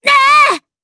Cecilia-Vox_Damage_jp_03.wav